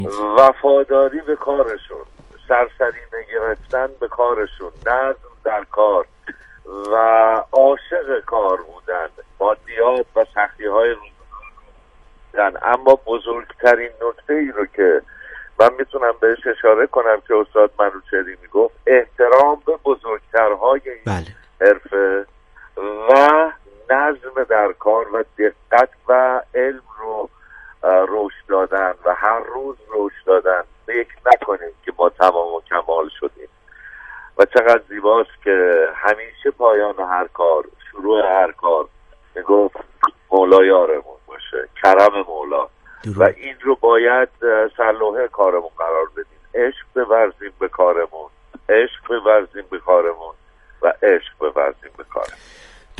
گفت‌وگویی به یاد صدای گویای نهج‌البلاغه